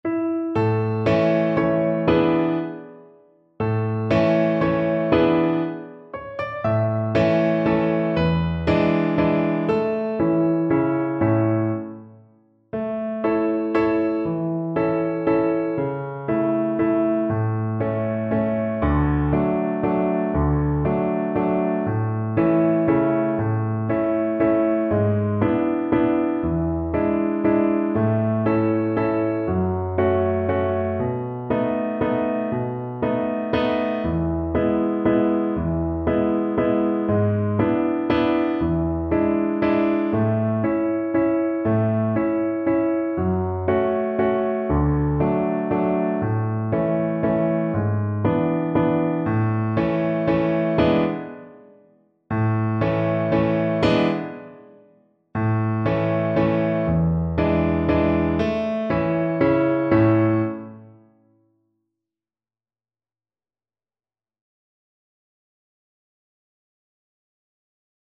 One in a bar . = c.54
3/4 (View more 3/4 Music)
Pop (View more Pop Violin Music)